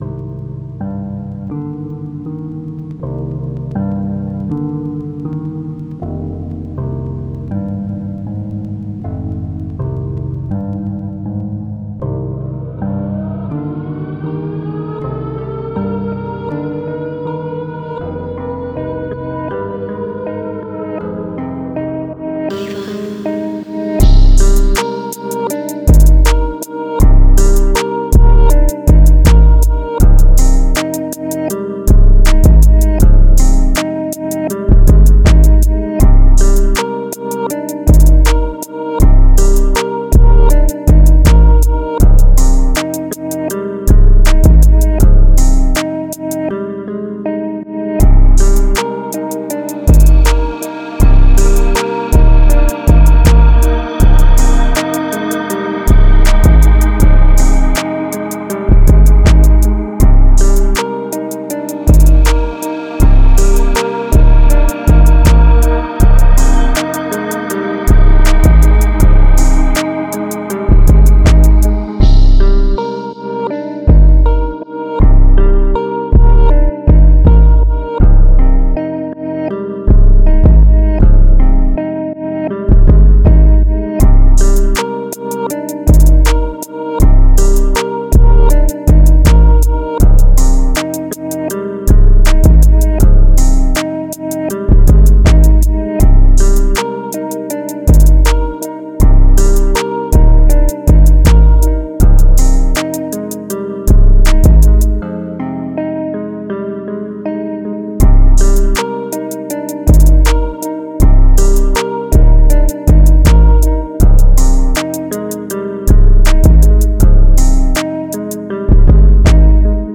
Time – (2:48)　bpm.160